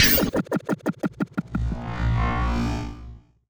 Robot Whoosh Notification 3.wav